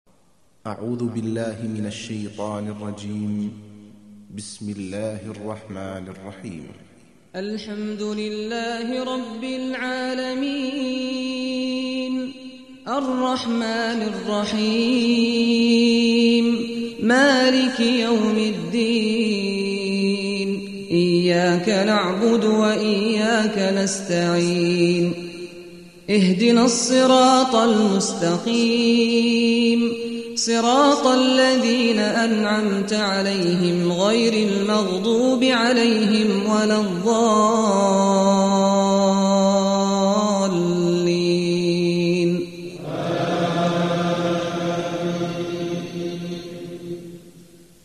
récitation mp3 - Hafidh Sâd al-Ghamidi (qdlfm) - 353 ko ;
001-Surat_Al_Faatiha_(Prologue)_Cheikh_Saad_Al_Ghamidi.mp3